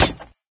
woodmetal2.ogg